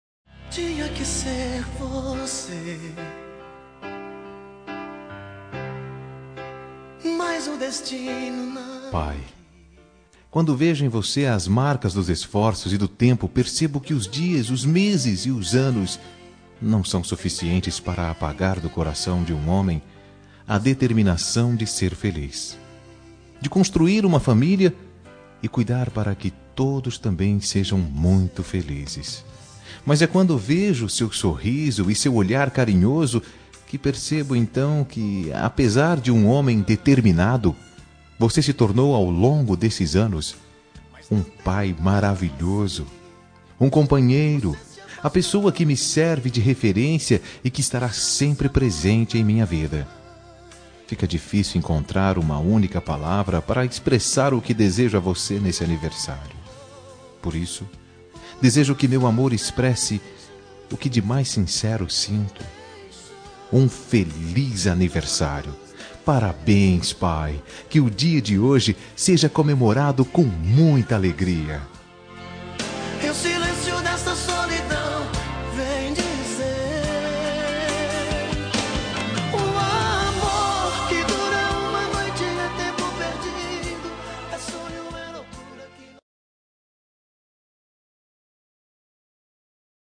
Telemensagem de Aniversário de Pai – Voz Masculina – Cód: 1504